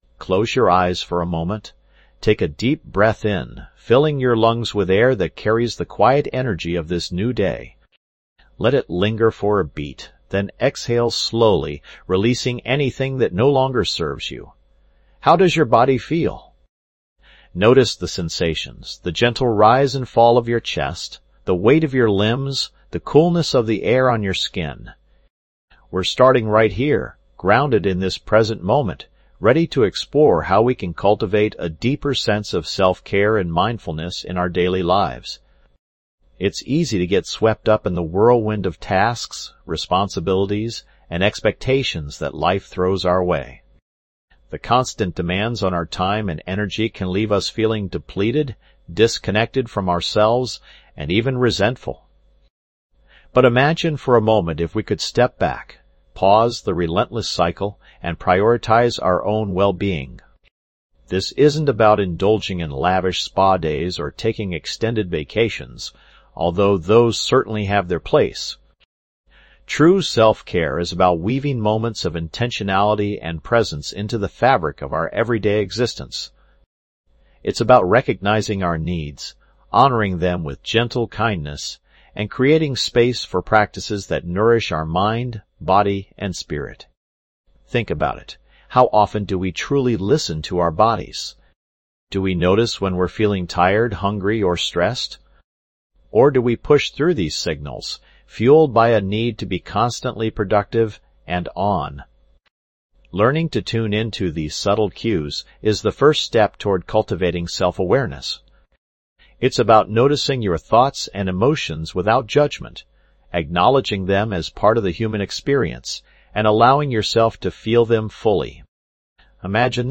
This podcast delivers powerful affirmations designed to quiet the negative voice in your head and empower you to take confident action towards your goals. Through daily guided meditations and inspiring messages, we'll work together to reprogram your subconscious mind, cultivate self-belief, and replace limiting beliefs with empowering ones.